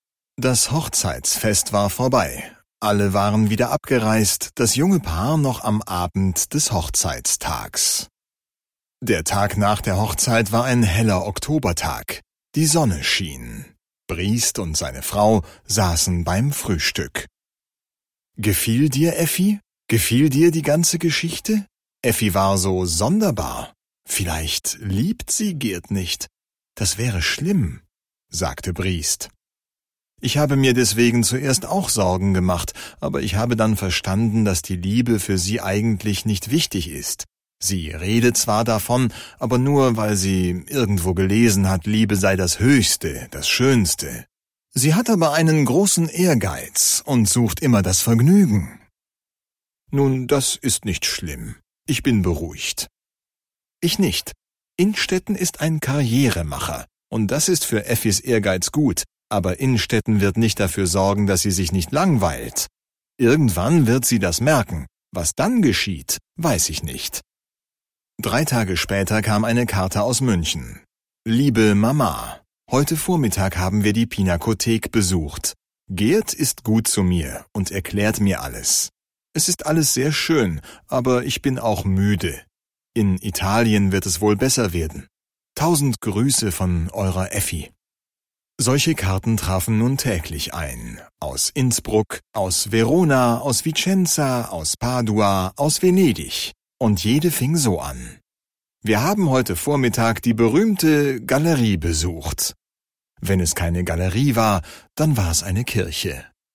Effi Briest (DE) audiokniha
Ukázka z knihy